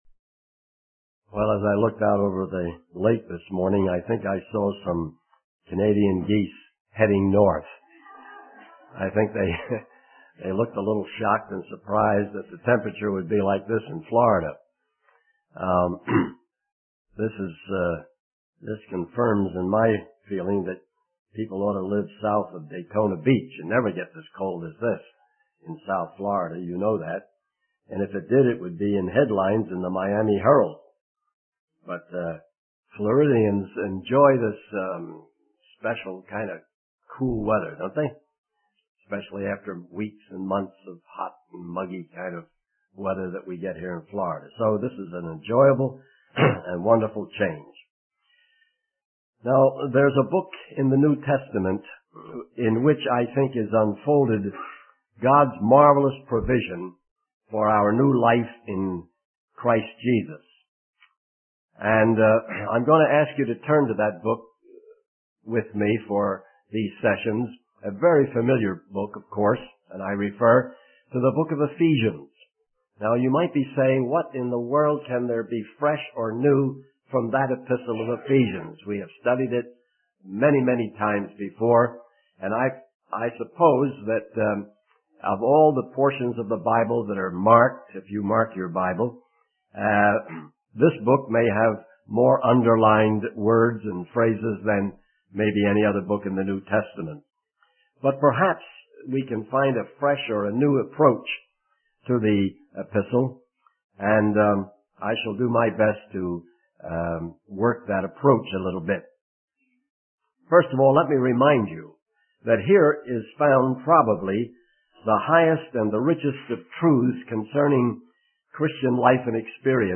In this sermon, the preacher focuses on the Apostle Paul's prayer for the Ephesians. He highlights three main requests that Paul makes in his prayer. Firstly, Paul wants the Ephesians to understand the hope of their calling, which will encourage and uplift them.